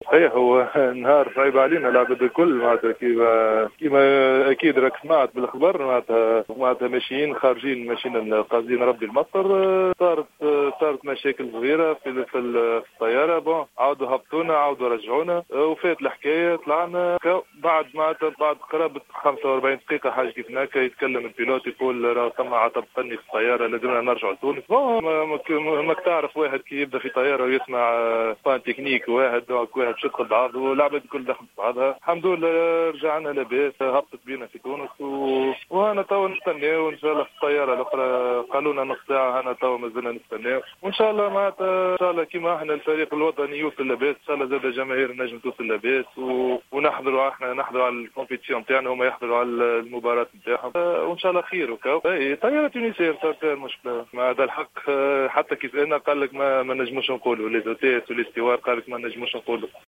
لاعب المنتخب الوطني للكرة الطائرة